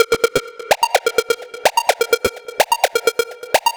Bubble _ Squeak Bb 127.wav